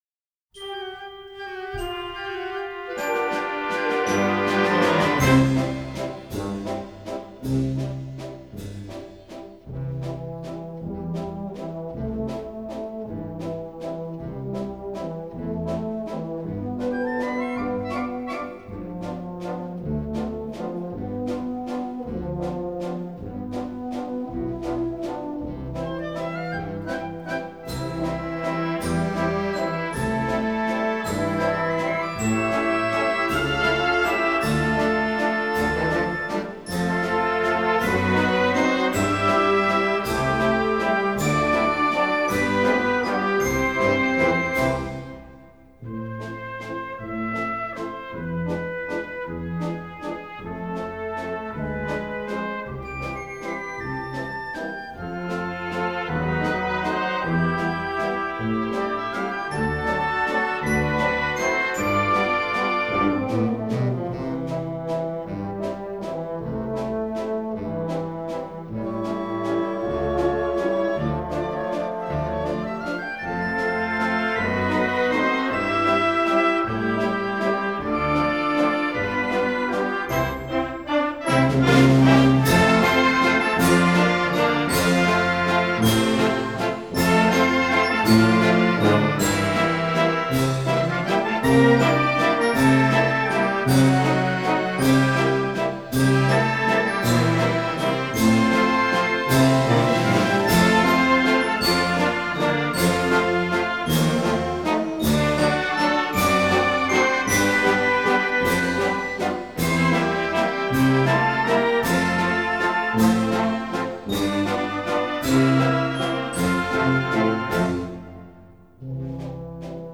Wienervalssi